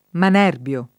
[ man $ rb L o ]